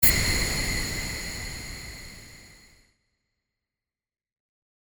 シュキーン
/ F｜演出・アニメ・心理 / F-80 ｜other 再構成用素材